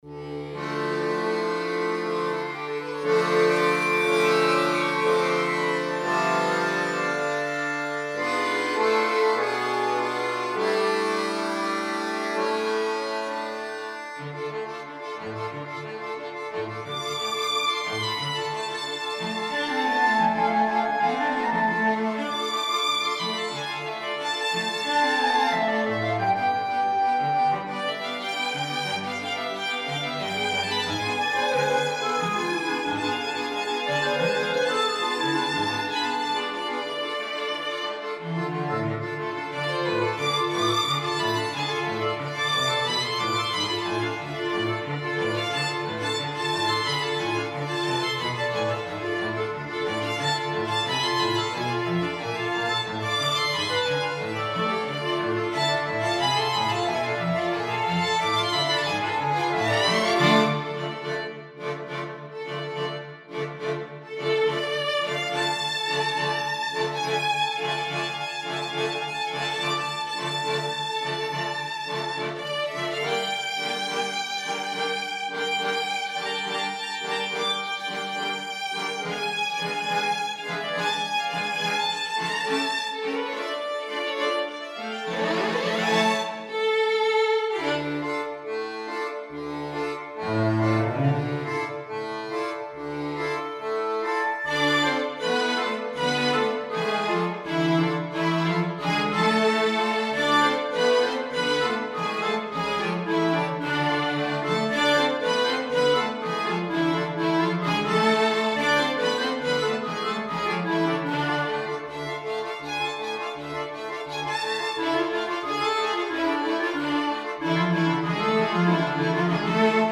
There are solo parts for Violin (Primary) and Clarinet.